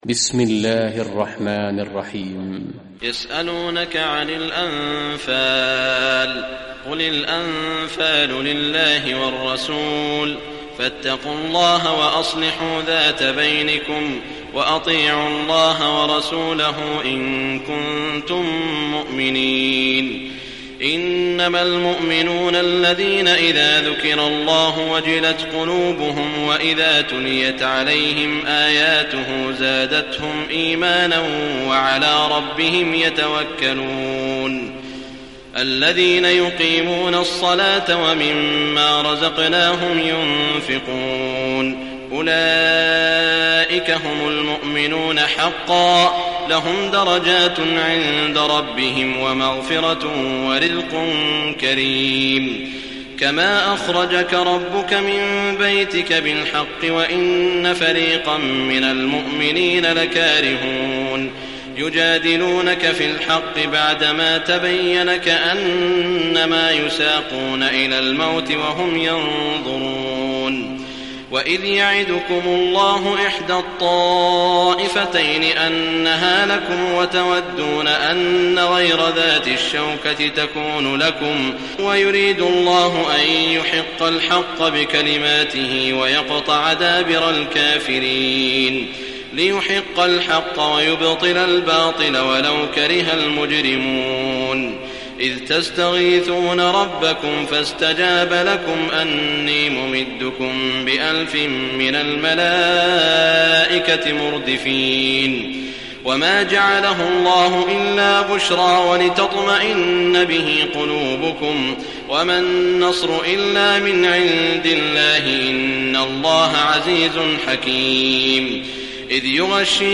Surah Al-Anfal Recitation by Sheikh Saud Shuraim
Surah Al-Anfal, listen or play online mp3 tilawat / recitation in the voice of Sheikh Saud al Shuraim.